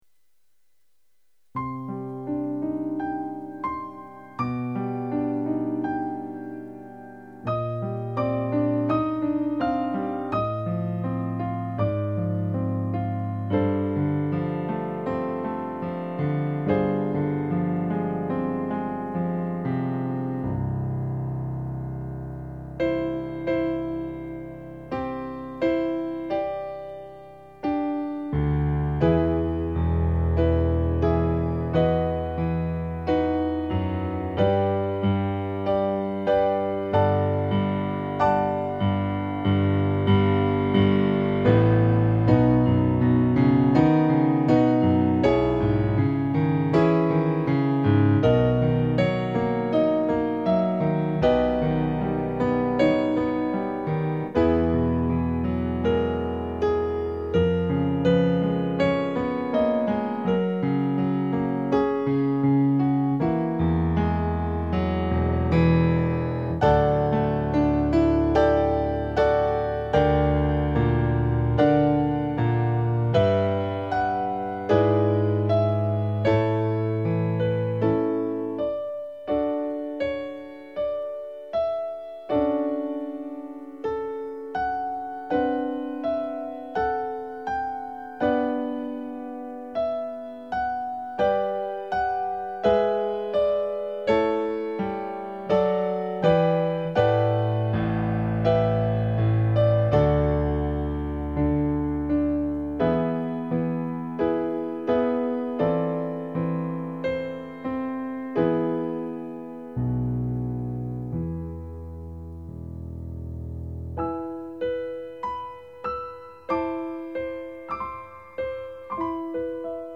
Voicing/Instrumentation: Piano Solo We also have other 41 arrangements of " Oh, Come All Ye Faithful ".
Christmas